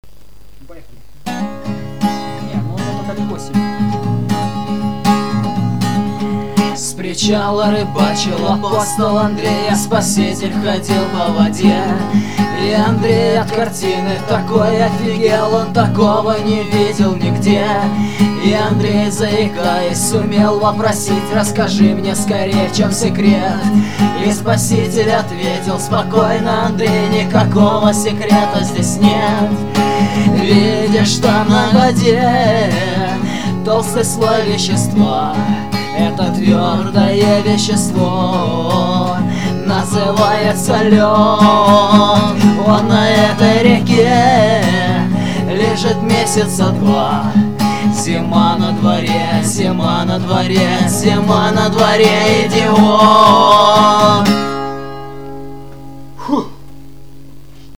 Слушать на гитаре